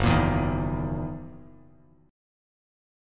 Gb13 chord